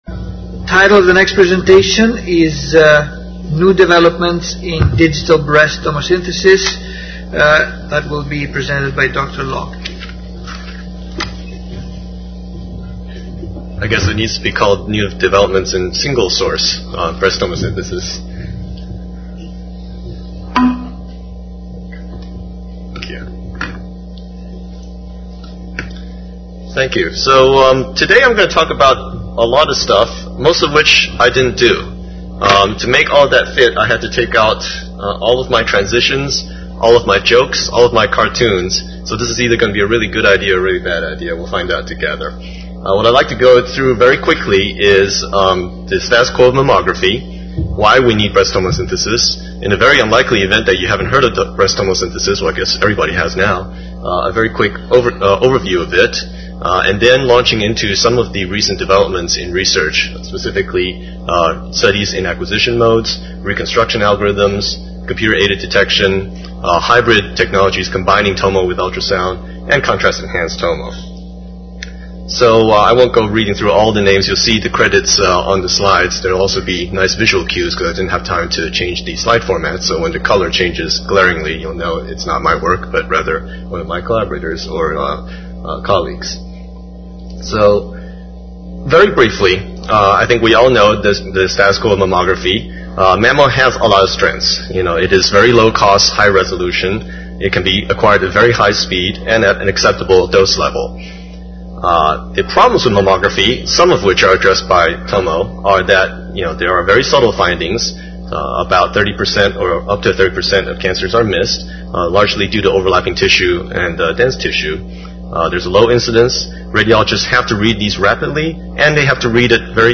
49th AAPM Annual Meeting - Session: Advances in X-ray Imaging